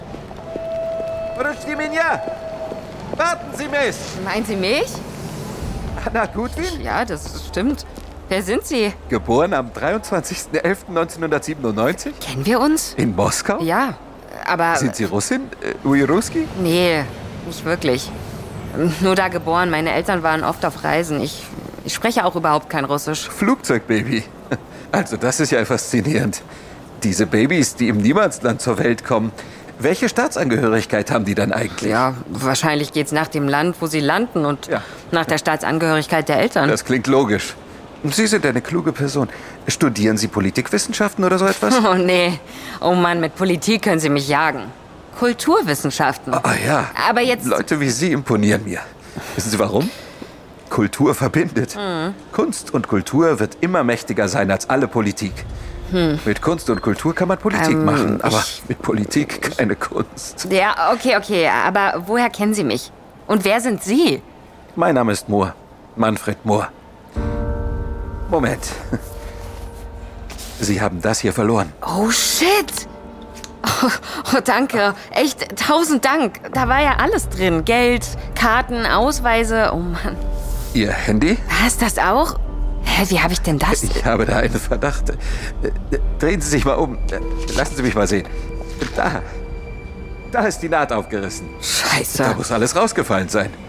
Mittel plus (35-65)
Audio Drama (Hörspiel)